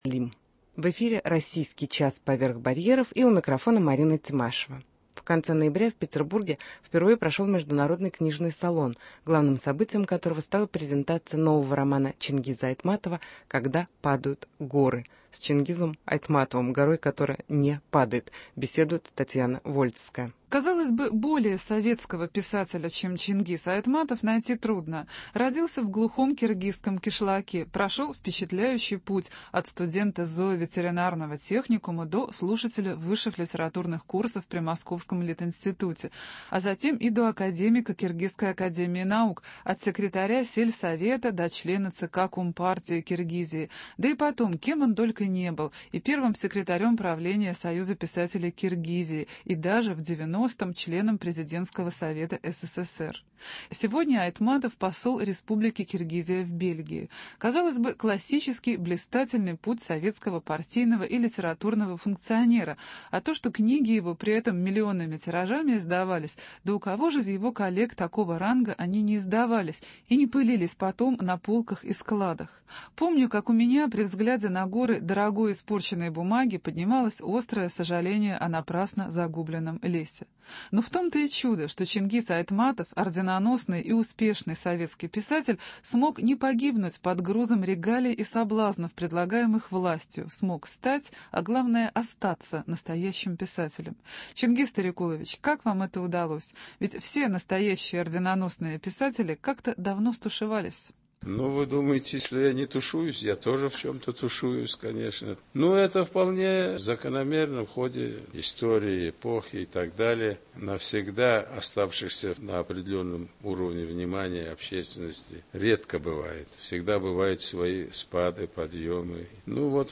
Интервью с Чингизом Айтматовым